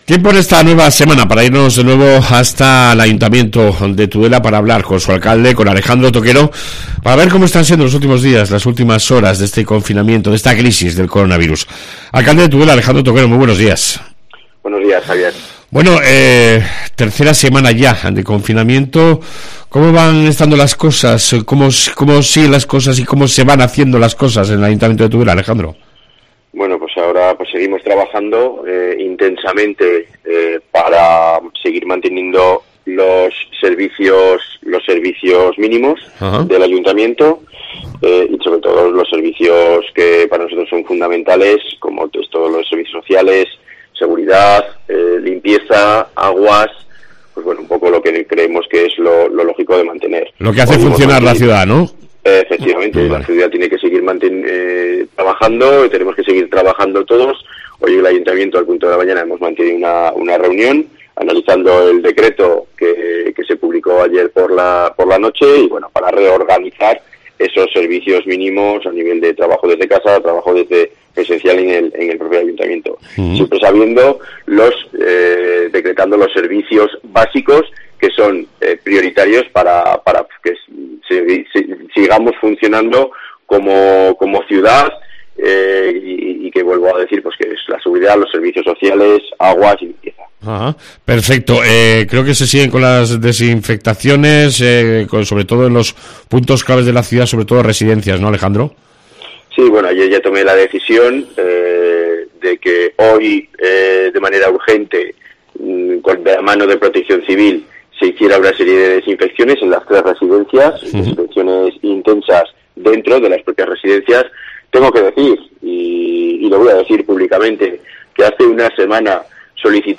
AUDIO: Alejandro toquero, Alcalde de Tudela, nos cuenta la última hora en esta crisis del Coronavirus